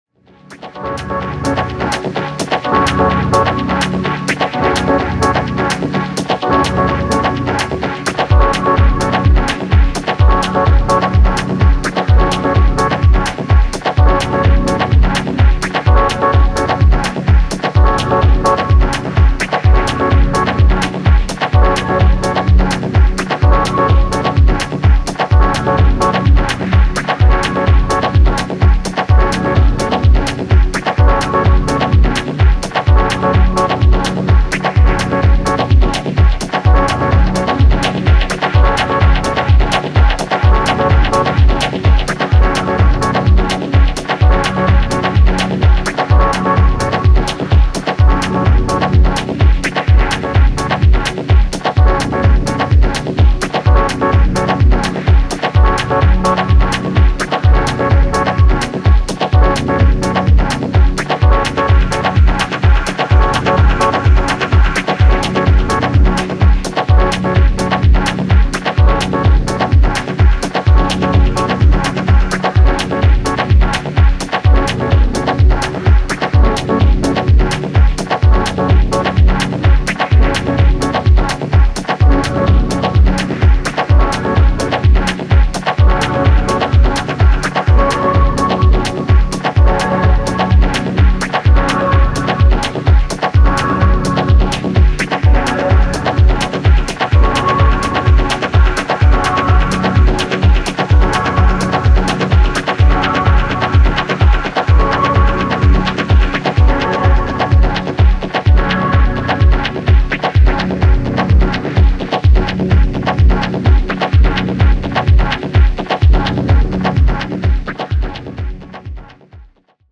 心地良く鼓膜を刺激する霞がかったダブコードと柔らかく跳ねたキックがとても上質。